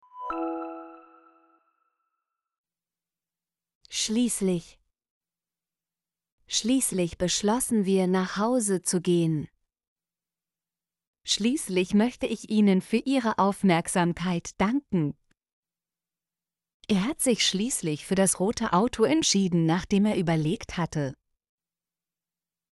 schließlich - Example Sentences & Pronunciation, German Frequency List